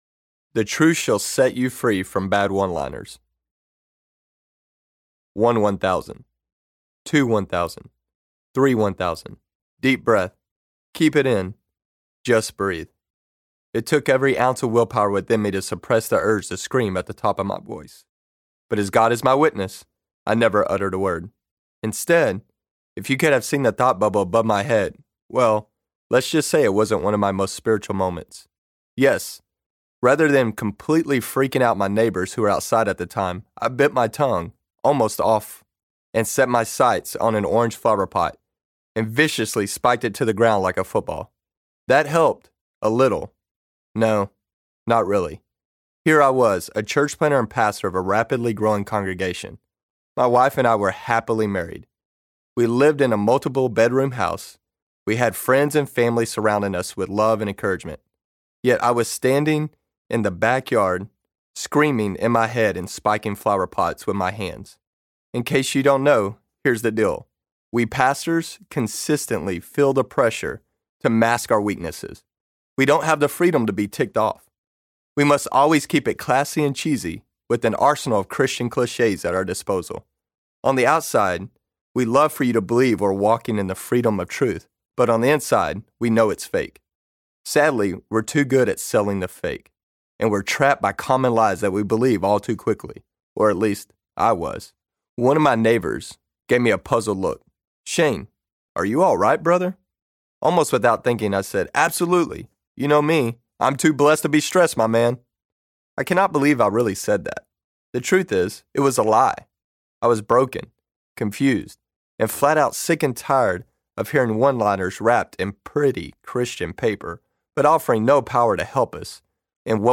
9 Common Lies Christians Believe Audiobook
Narrator
5.13 Hrs. – Unabridged